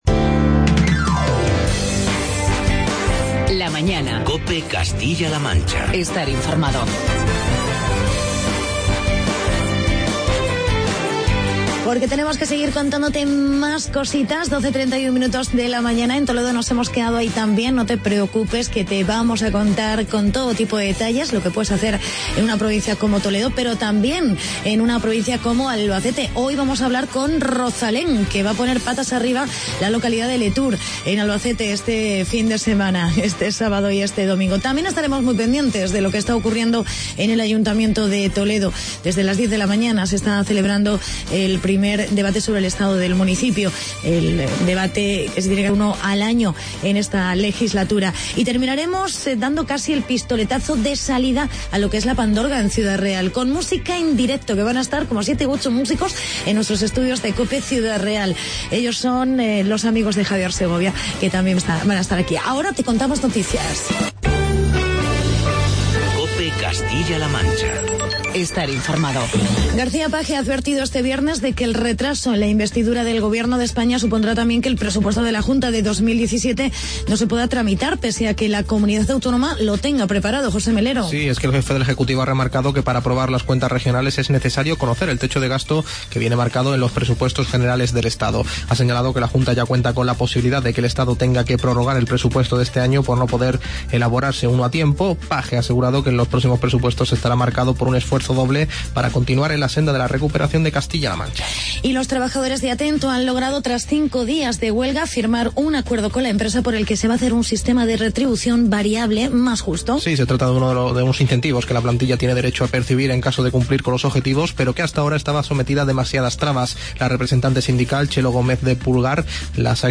Entrevista con el portavoz Municipal del PP, Jesús Labrador sobre el Debate del Estado del Municipio.